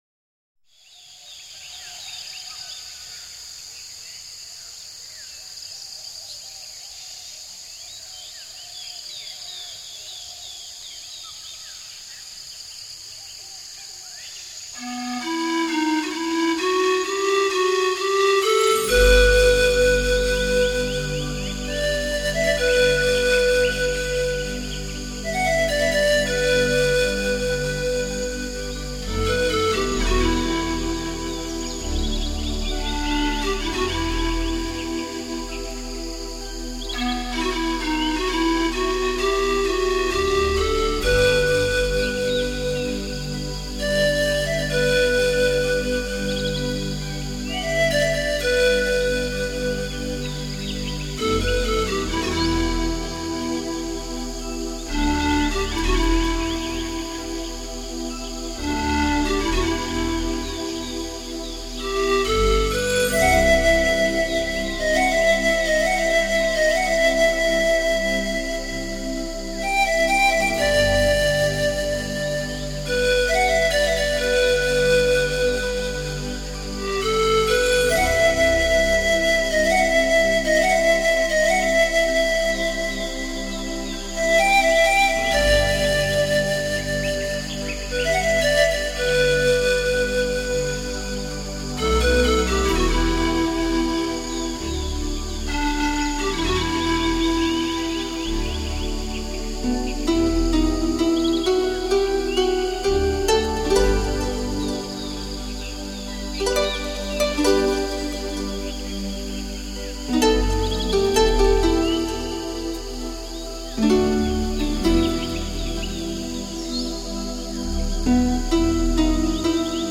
新世纪
你还可以听到採自阿尔卑斯山原始森林的鸟鸣，以及罗亚尔河的溪流声，带给你置身山林的新听觉享受！